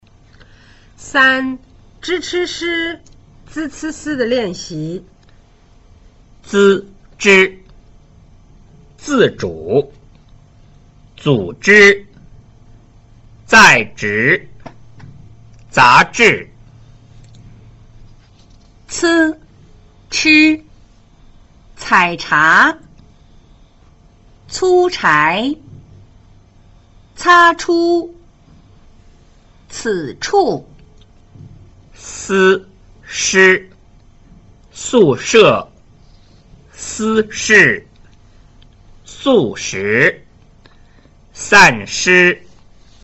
3. zh   ch   sh   z   c   s   的練習